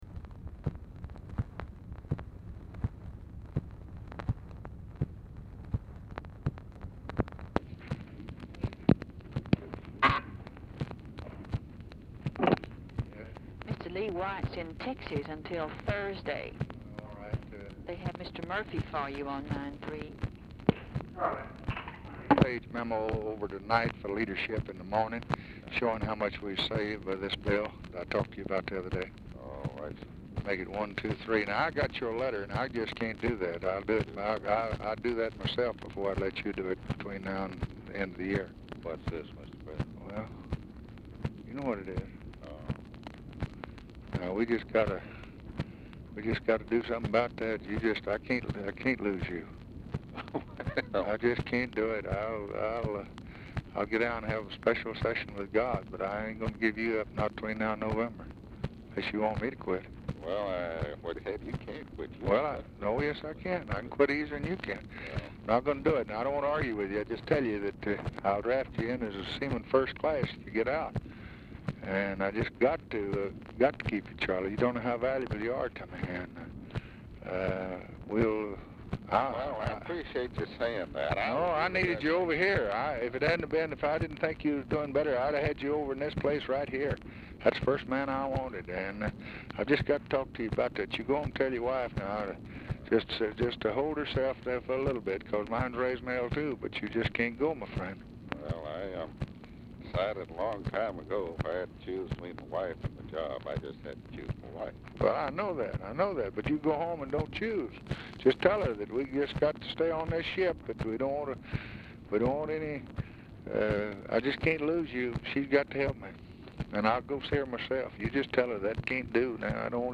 OFFICE SECRETARY
Oval Office or unknown location
Telephone conversation
Dictation belt